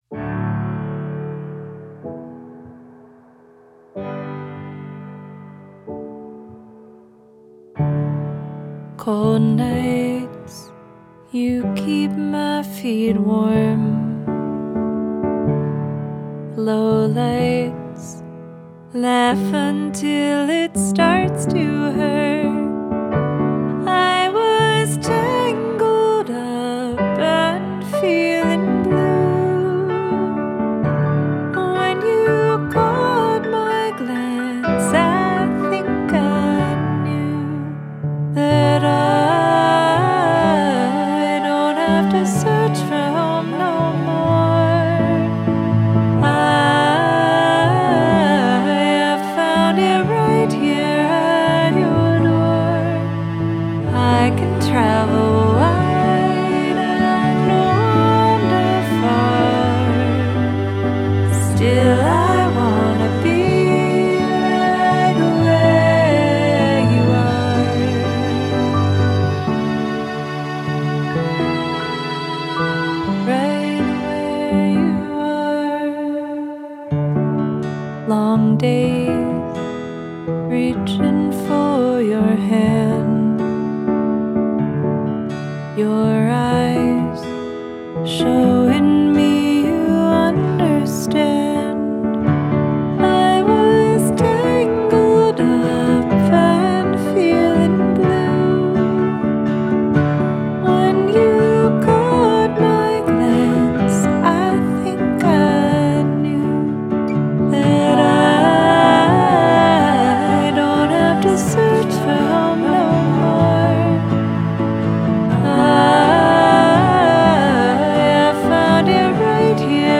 vocals, piano